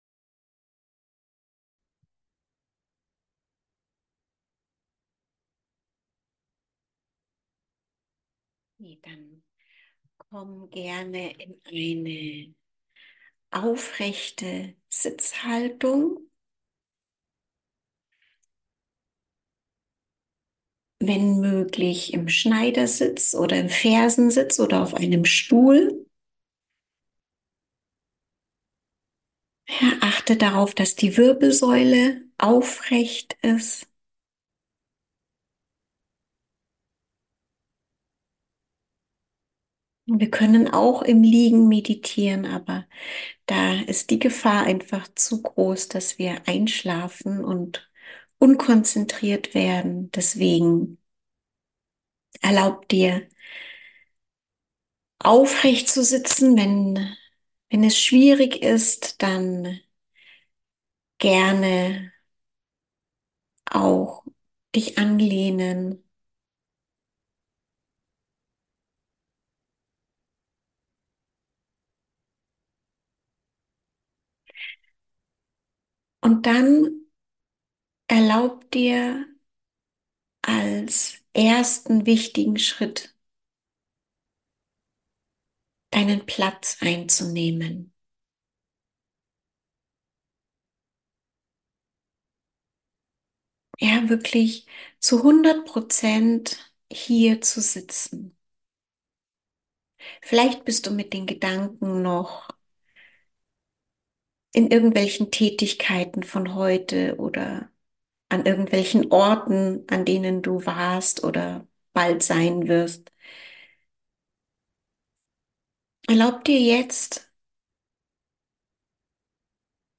#45: Meditation Ankommen im Jetzt ~ Zwischen Erde und Himmel - meditieren, heilen und hiersein Podcast